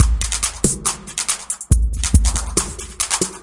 描述：用氢气制作的70bpm鼓循环
Tag: 节拍 电子